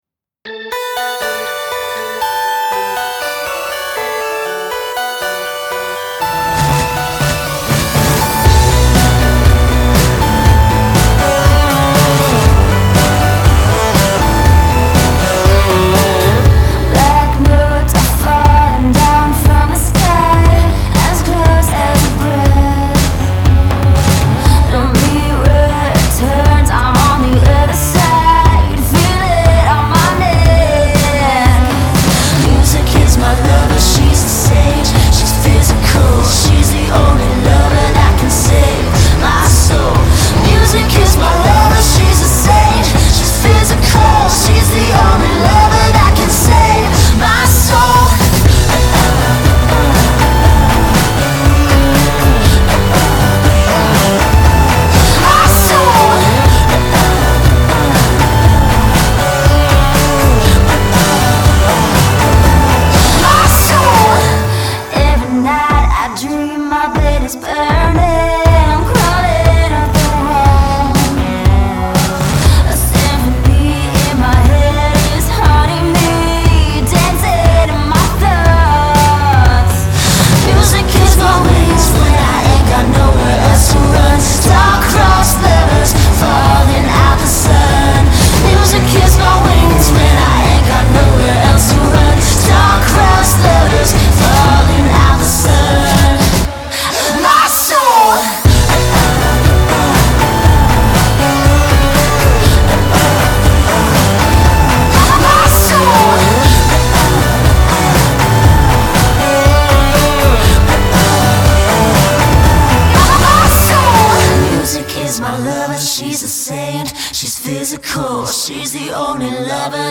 Nashville-based duo